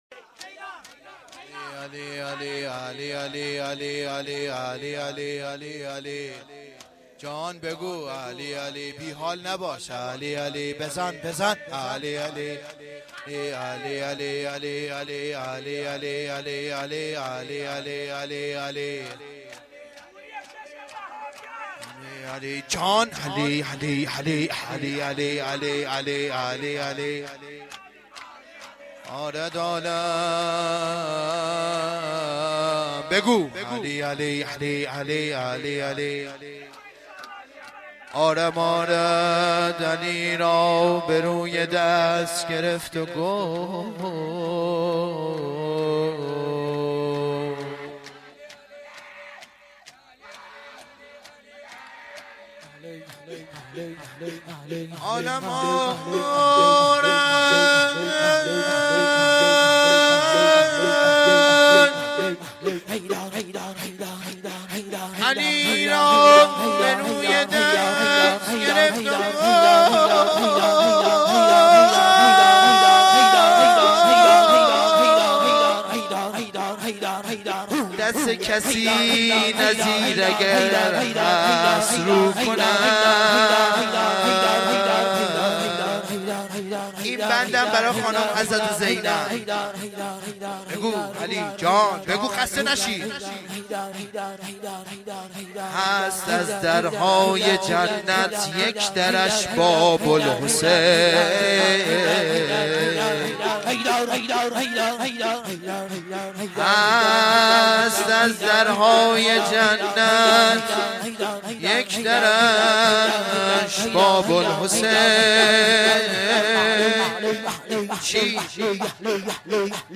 روضه العباس